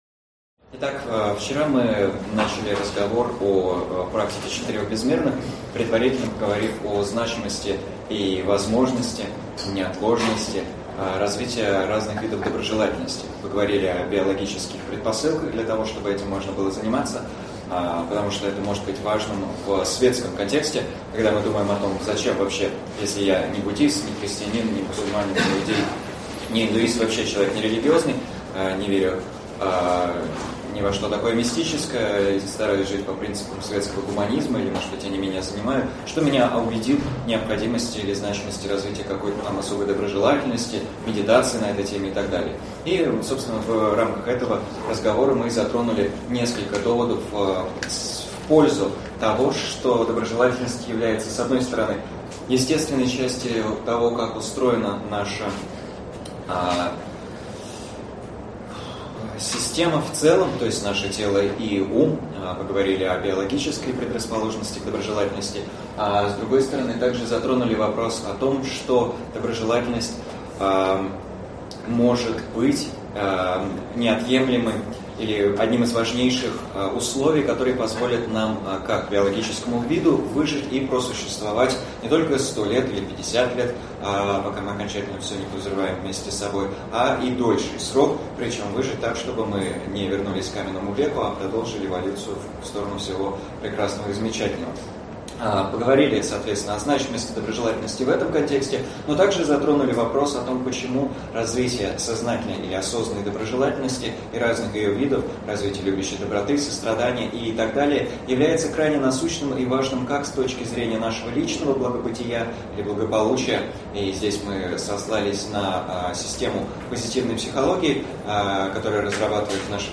Аудиокнига Осознанная доброжелательность и четыре безмерных. Часть 4 | Библиотека аудиокниг